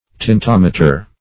Tintometer \Tint*om"e*ter\, n. [Tint + -meter.] (Physics)